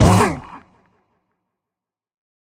minecraft / sounds / mob / warden / hurt_2.ogg
hurt_2.ogg